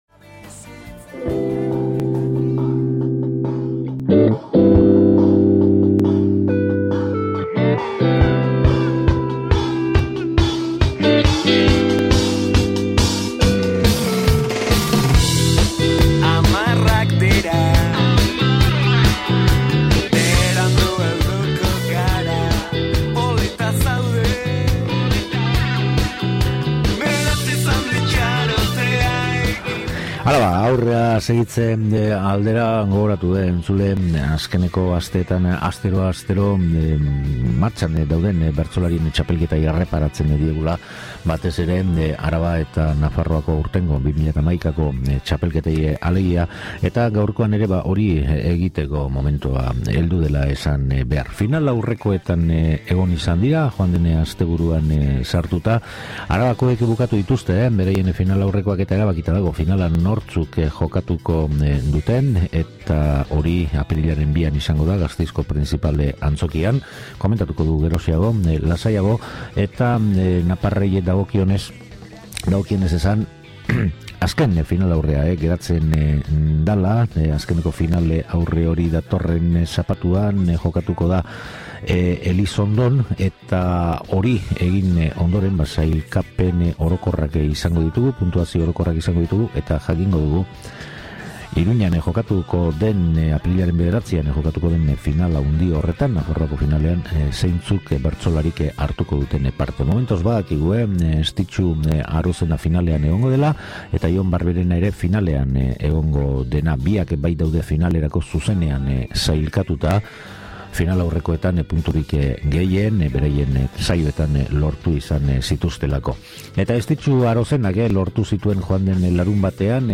Solasaldia
Nafarroakoari dagokionez, larunbata honetan Elizondon egingo den azken finalaurrekoaren zai geratu behar. halaber, BBK sariketa ere hasi dela gogoratu behar. Zenbait bertso entzuteko aukera ere baduzu, entzule.